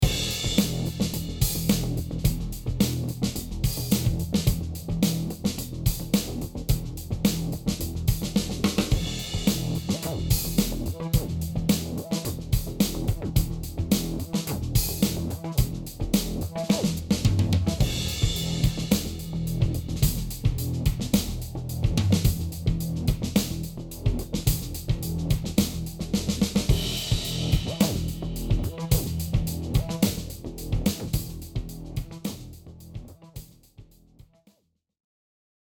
下にあるオーディオサンプルは、全く同じ素材のマスターフェーダーに、HEarを使用したもの・していないものの2つのサンプルです。
そしてこちらが、マスターフェーダーの最後にHEarを使ってみたもの。
上のサンプル（HEarなし）では左右に思いっきり広がっているシンセベースが、下のサンプル（HEarあり。スピーカー音場を再現）ではほどほど心地よいくらいの広がりに聞こえます。
また、ローエンドの聞こえ方も違いますね。